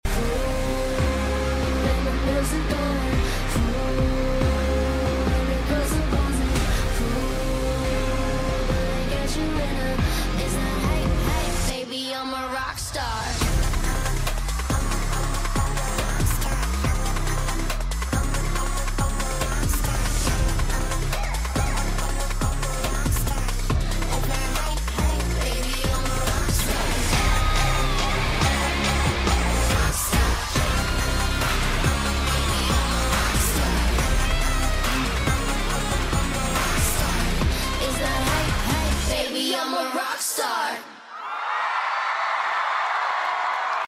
LIVE PERFORMANCE GLOBAL CITIZEN FESTIVAL NYC